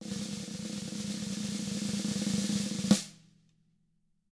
TAM3ROLL3D-L.wav